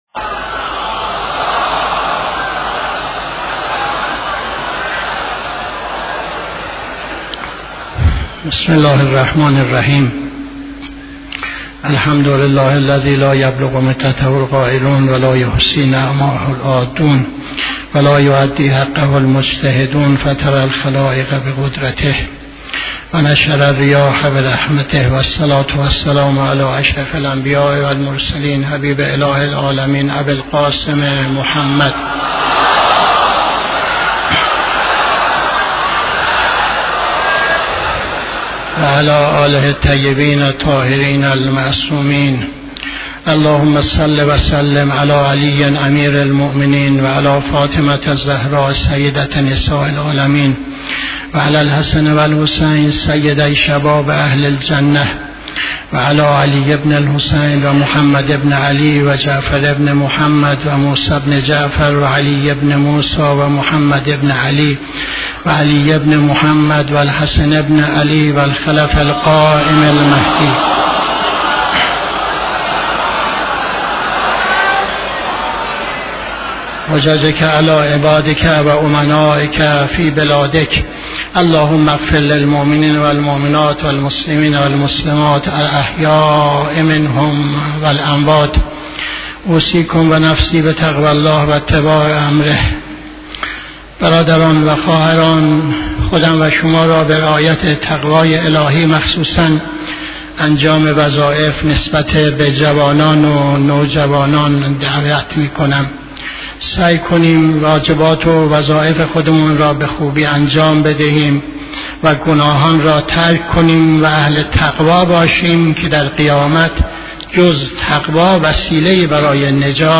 خطبه دوم نماز جمعه 14-10-80